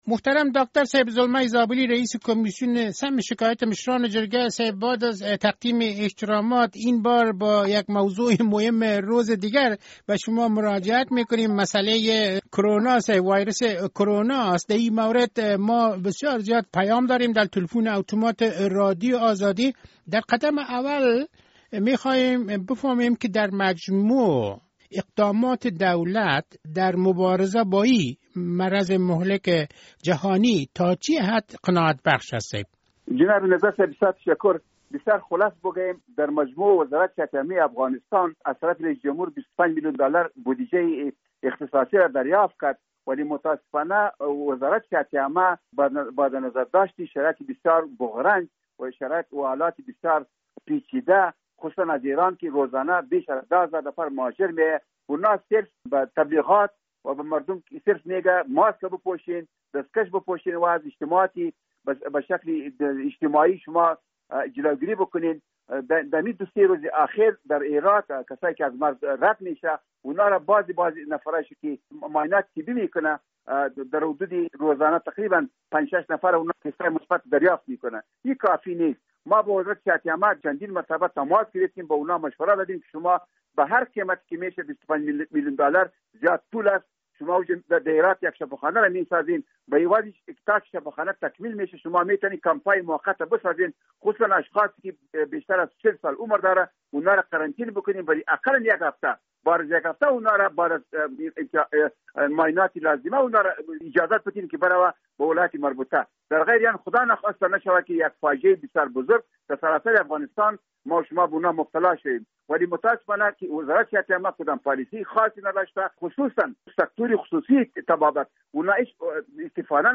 مصاحبه با زلمی زابلی